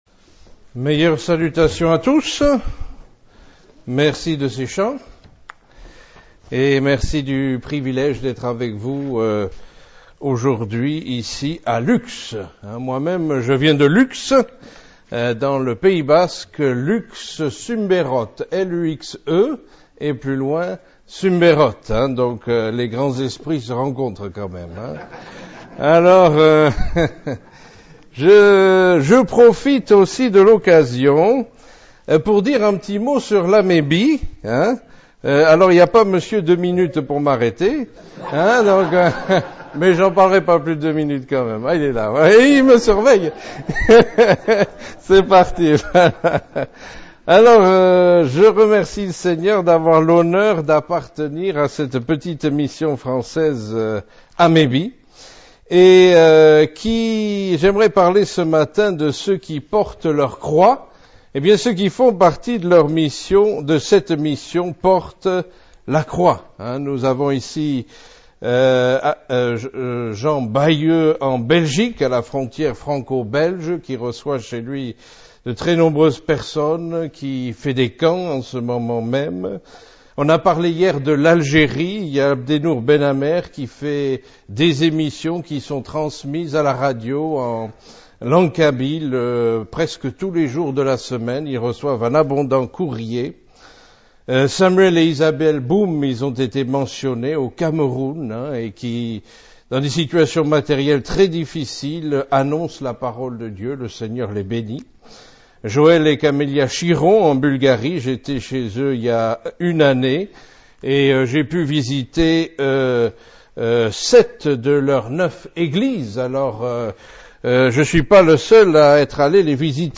A l’occasion de la convention Biblique 2006 de l’Alliance Baptiste de France, ce thème de la croix fut développé en profondeur.